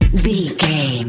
Aeolian/Minor
synthesiser
drum machine
hip hop
Funk
neo soul
acid jazz
r&b
energetic
bouncy
Triumphant
funky